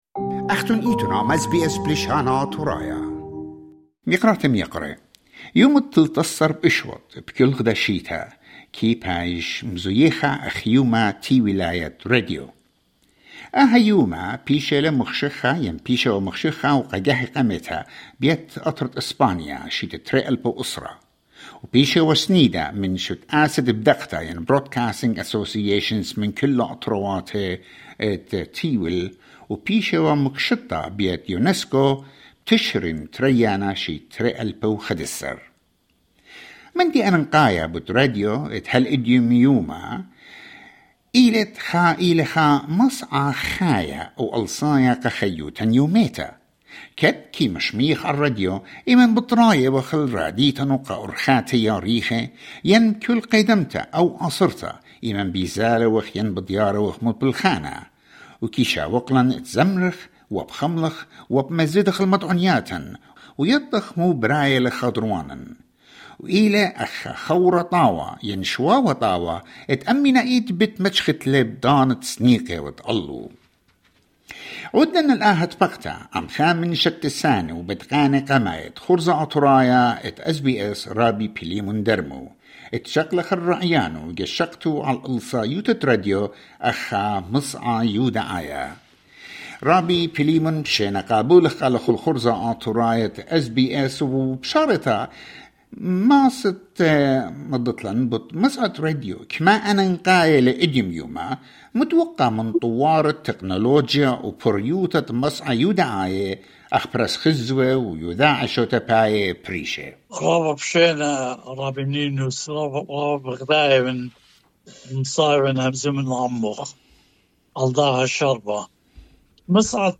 SBS Audio Studio Credit: sbs.jpg